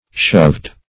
(sh[u^]vd); p. pr. & vb. n. Shoving.] [OE. shoven, AS.